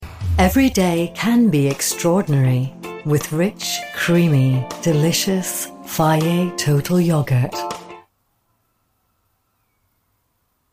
J'ai un léger accent écossais et ma voix a été décrite comme sophistiquée, douce, chaleureuse et autoritaire.
Microphone Sennheiser
Anglais (écossais)
Âge moyen